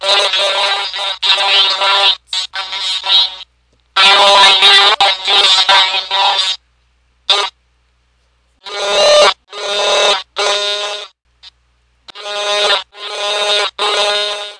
描述：深度自动化的男性语音提醒即将发生的影响。
标签： 电子 星河 警告 手机 autovoice 警报 机器人 警报报警 冲击